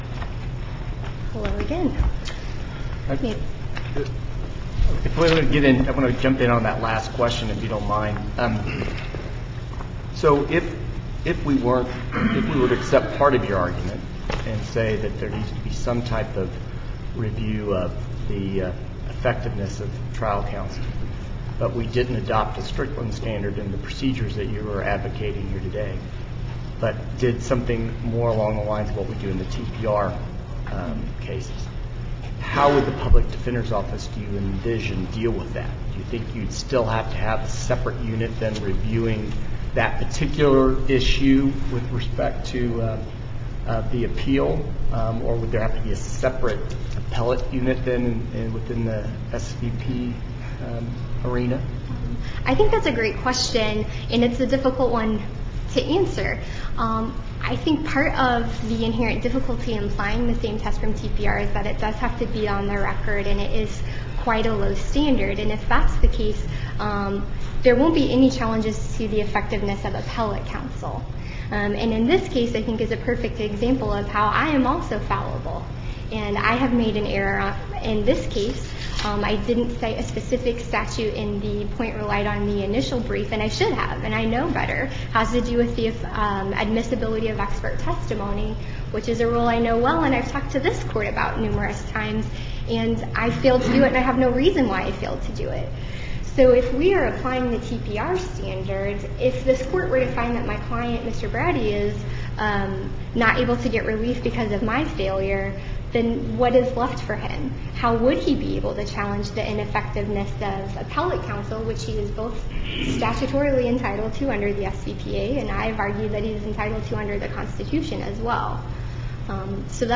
MP3 audio file of oral arguments before the Supreme Court of Missouri in SC96830